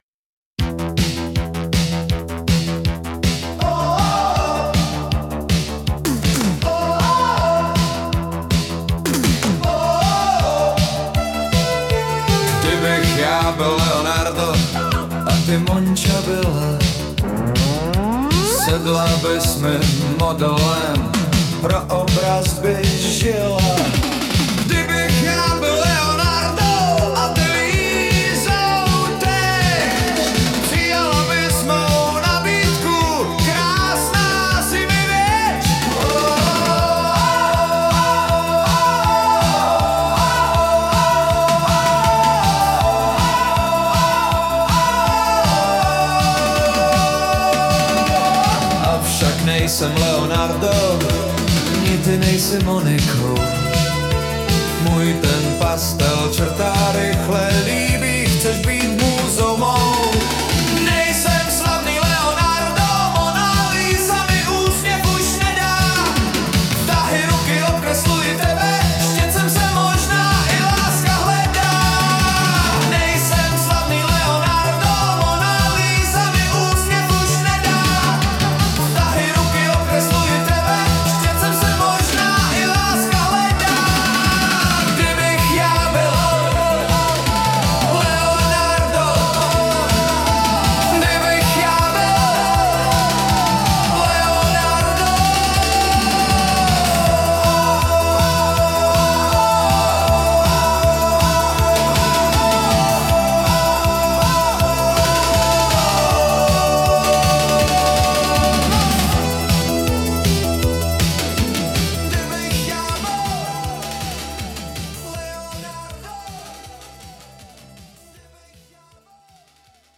hudba, zpěv: AI
Nj, řeč je o italském velikánovi, tak trochu italské retro vlny. ;-)